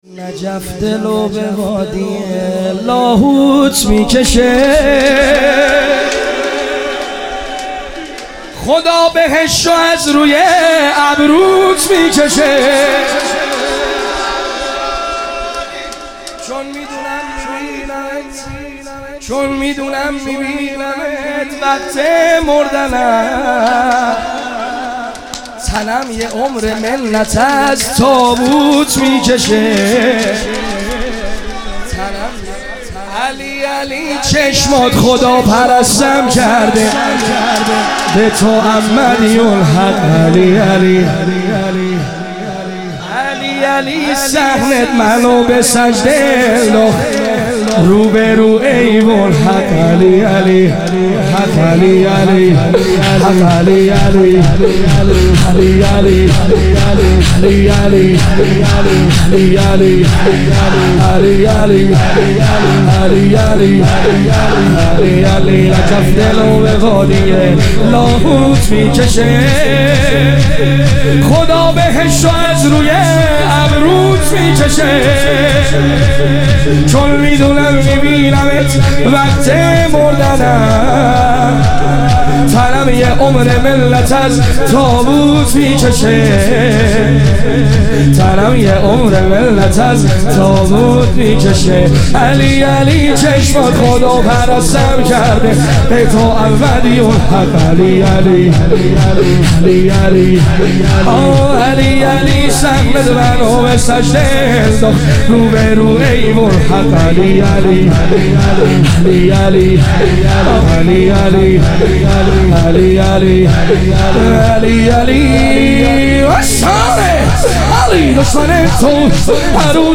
ایام فاطمیه اول - شور